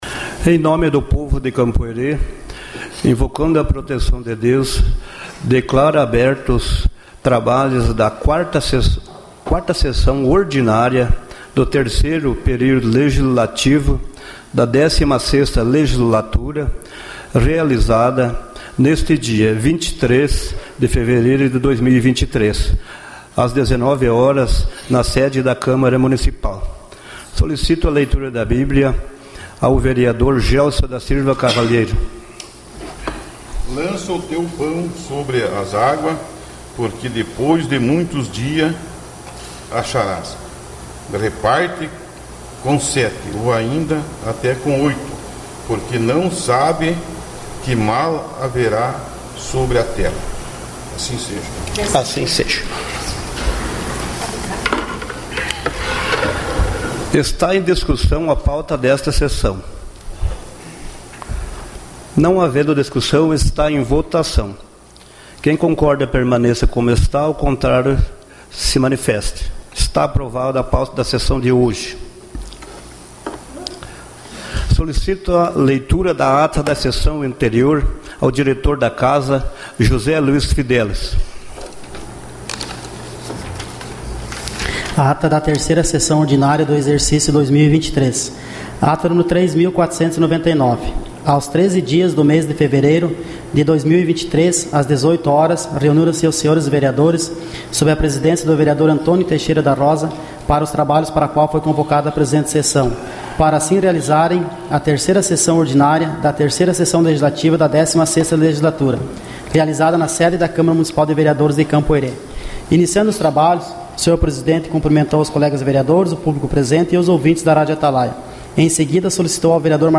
Gravação das Sessões